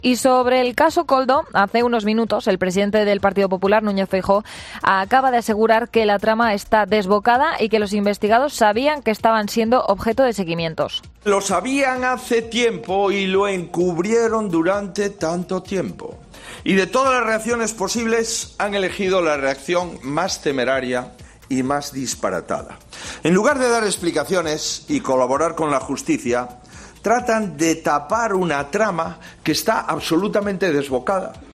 En un acto en Bilbao, donde ha quedado proclamado Javier de Andrés como candidato a lehendakari del PP, Feijóo ha reiterado, en alusión a esa investigación, que Sánchez y los socialistas "lo sabían hace tiempo y lo encubrieron" y ha criticado que hayan optado por la reacción "más temeraria y disparatada", tratando de "tapar una trama que está desbocada".